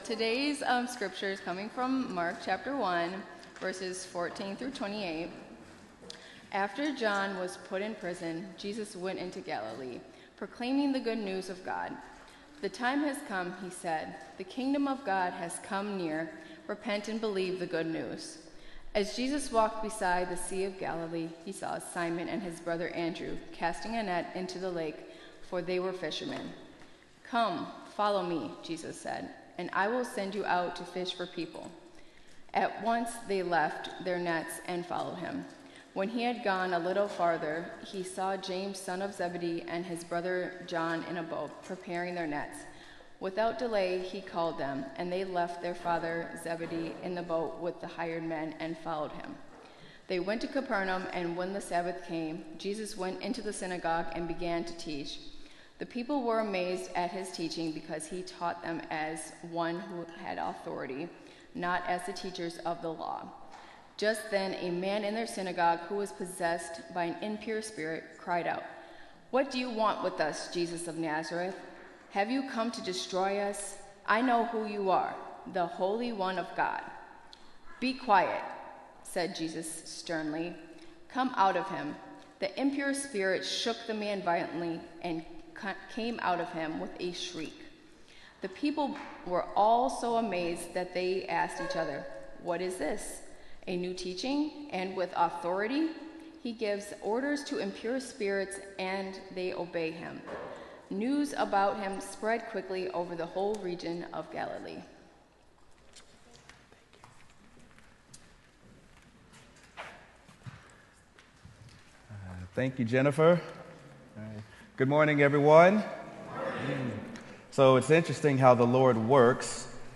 Sermon: Mark: The Authority of Jesus